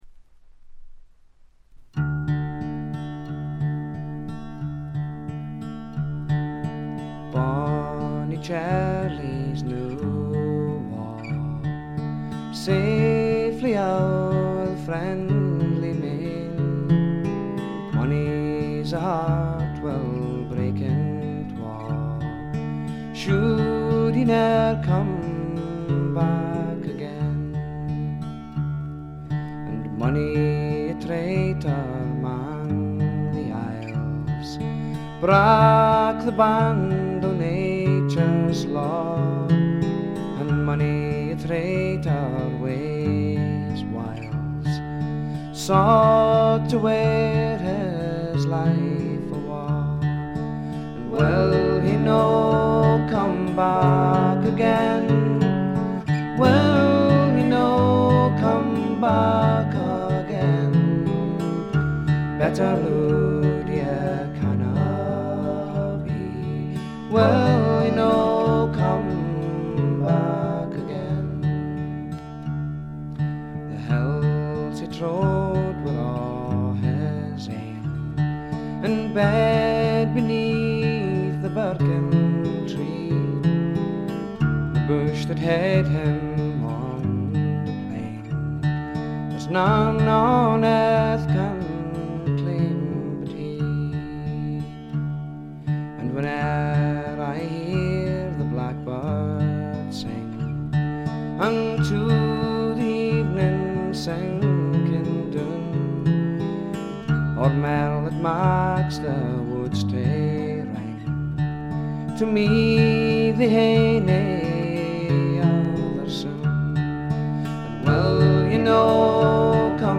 ほとんどノイズ感無し。
ギター、フィドル、バックヴォーカル等すべてダギー自身によるもの。彼の特徴である噛みしめるように紡ぎ出すあたたかな歌声を心ゆくまで味わってください。
試聴曲は現品からの取り込み音源です。
Instruments [All], Vocals [All] - Dougie MacLean
Recorded And Mixed At Dambuster Studios, Essex